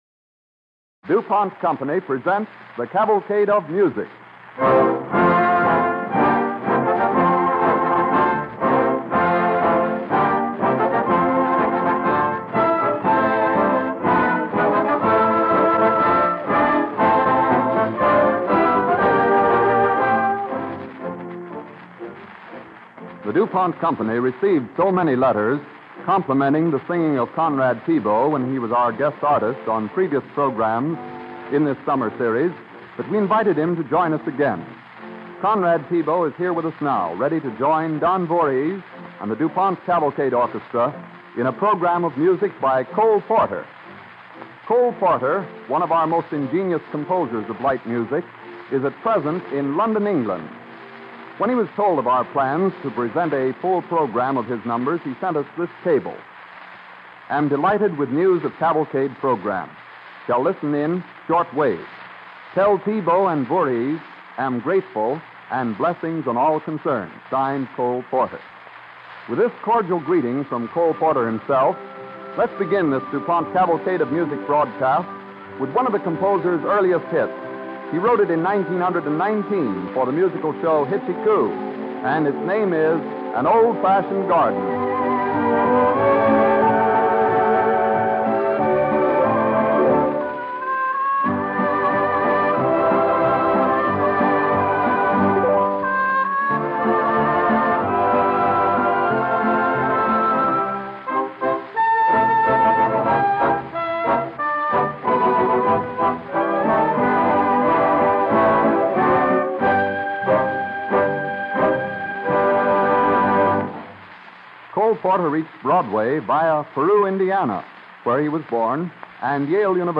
announcer